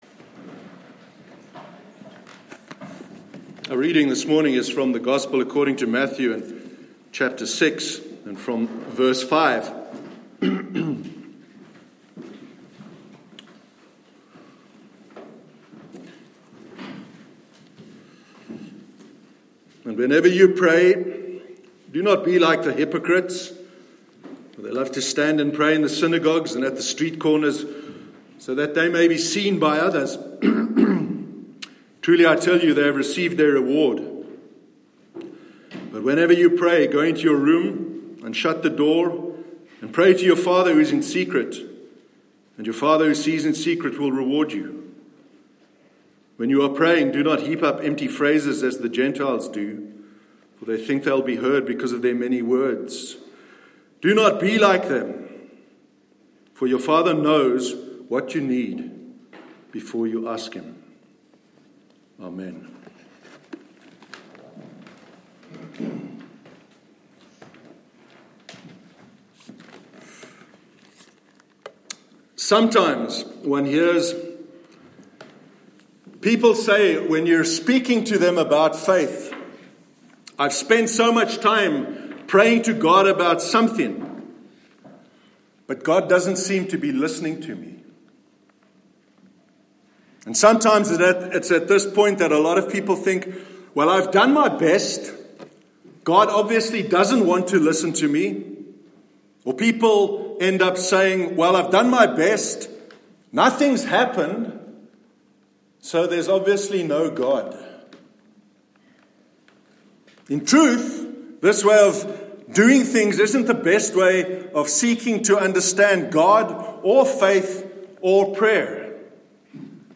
Sermon on Prayer- 8th September 2019
sermon-8-september-2019.mp3